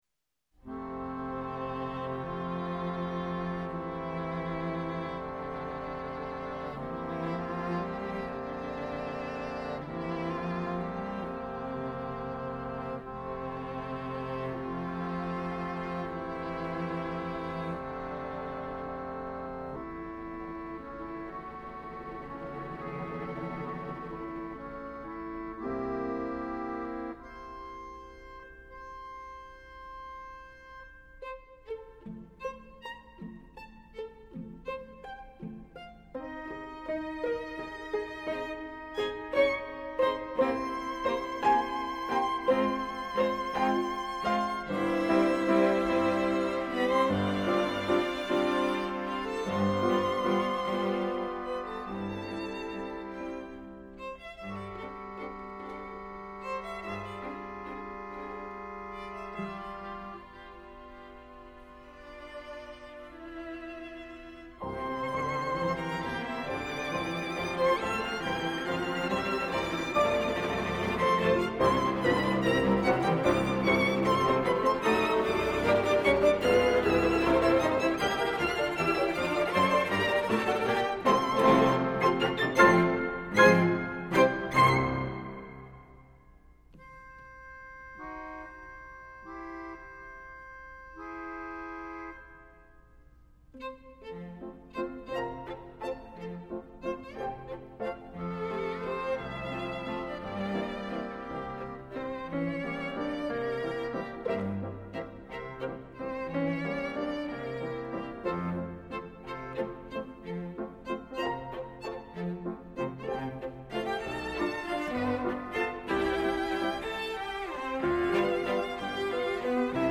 piano, quatuor à cordes, clarinette, flûte et harmonium
quatuor à corde, piano et harmonium